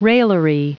Prononciation du mot raillery en anglais (fichier audio)
Prononciation du mot : raillery